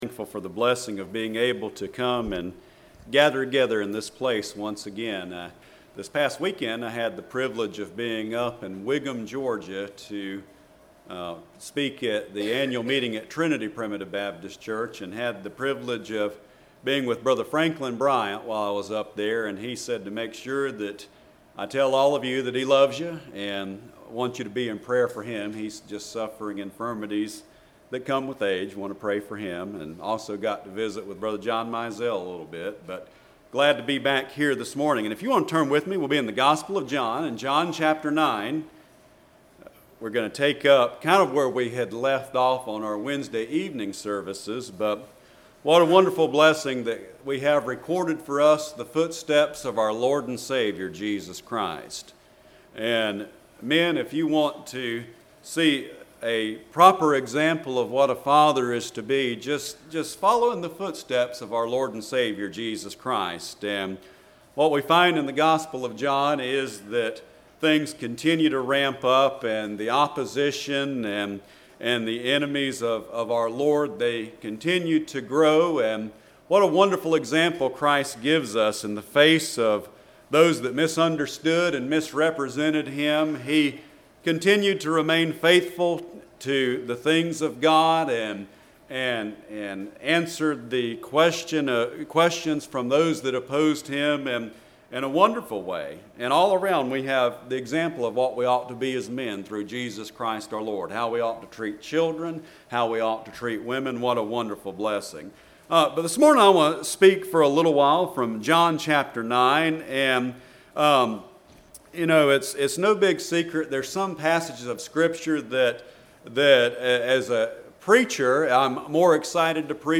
06/16/19 Sunday Morning